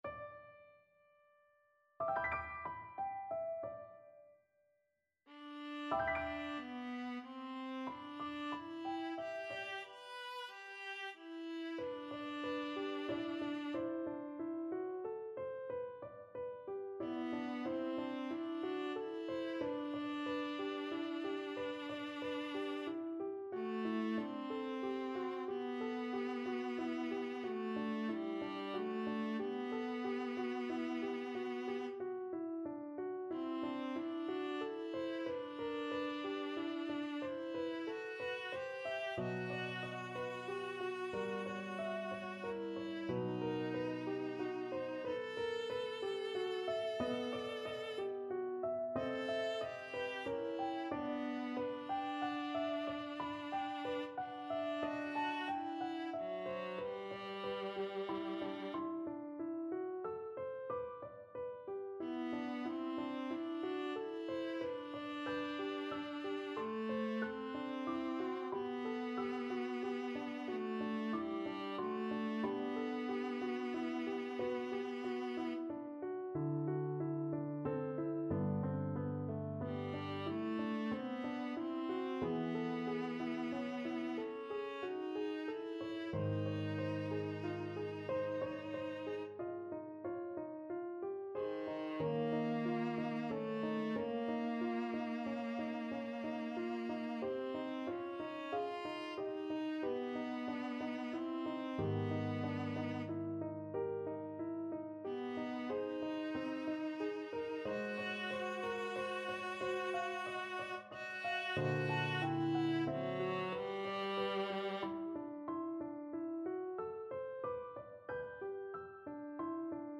Classical Mahler, Gustav Ich atmet' einen linden Duft No.2 from Ruckert Lieder Viola version
Viola
G major (Sounding Pitch) (View more G major Music for Viola )
6/4 (View more 6/4 Music)
Lento =92
Classical (View more Classical Viola Music)